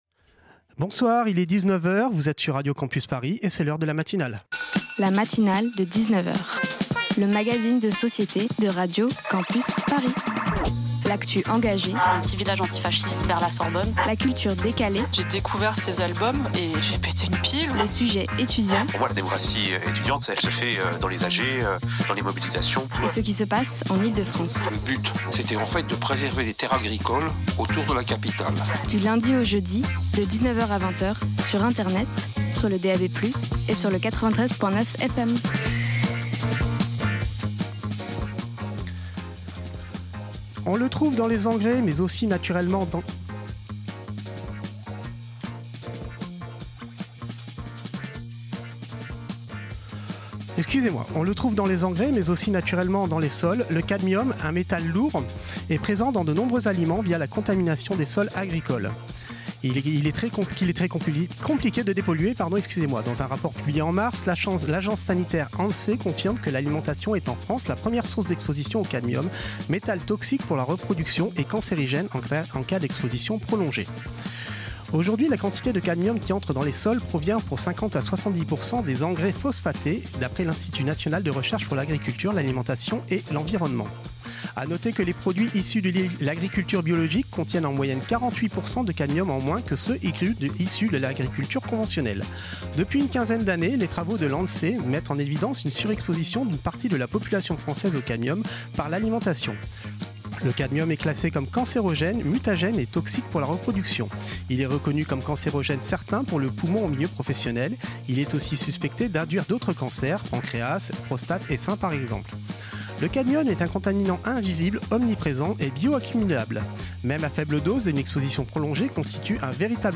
Reportage sur le groupe Okali & l'exposition Diseu...